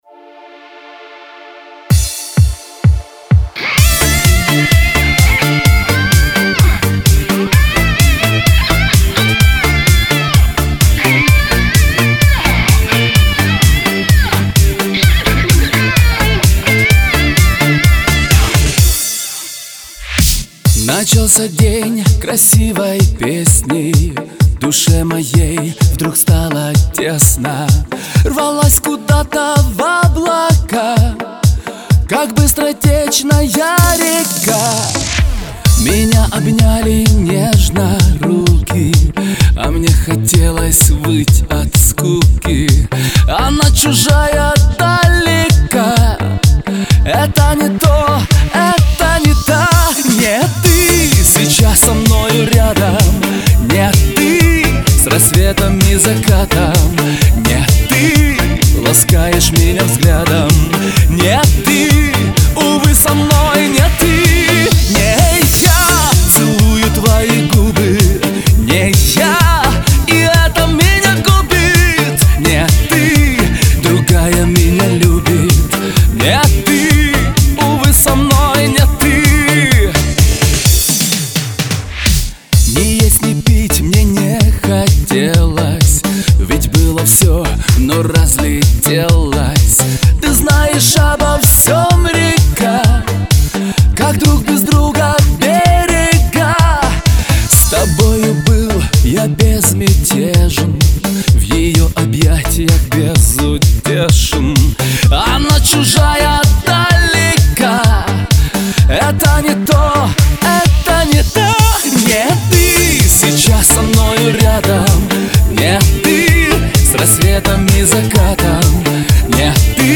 Категория: POP